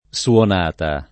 suonata [ SU on # ta ]